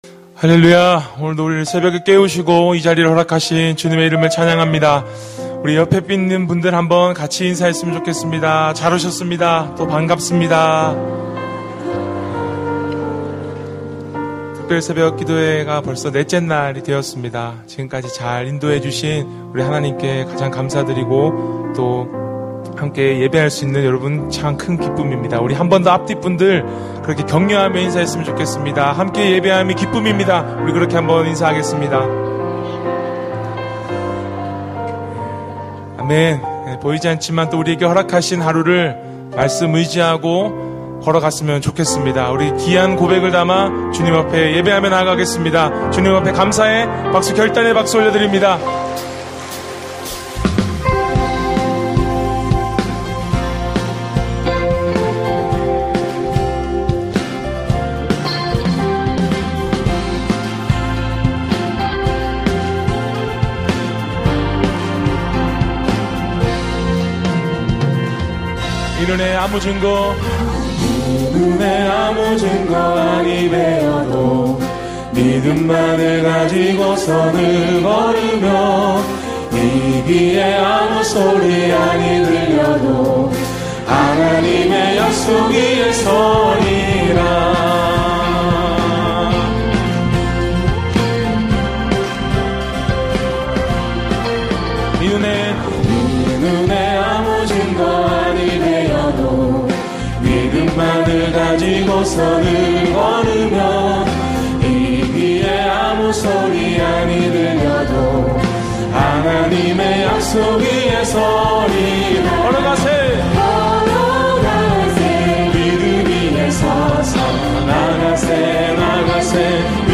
절기예배